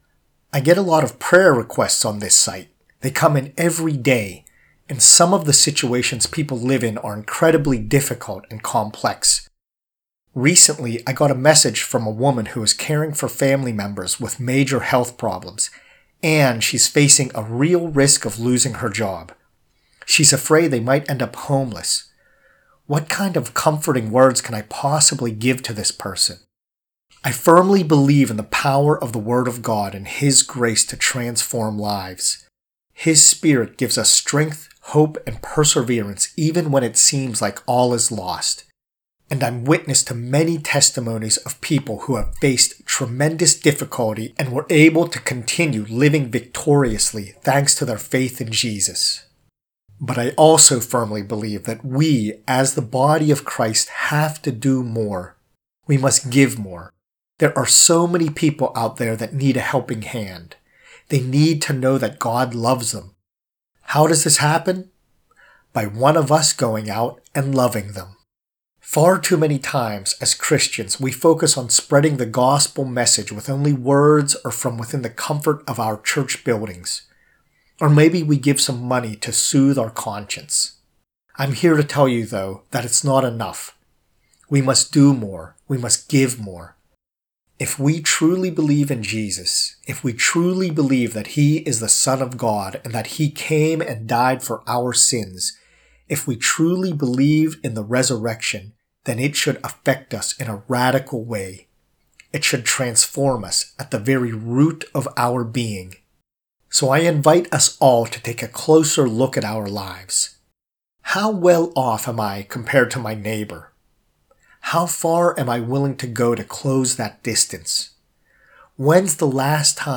prayer-to-give-more.mp3